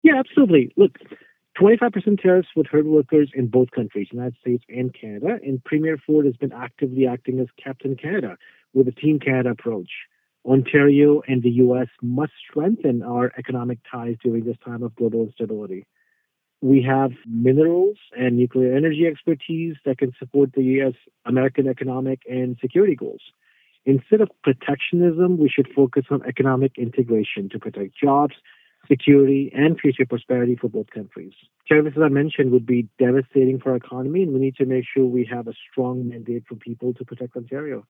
He was available by phone for this interview.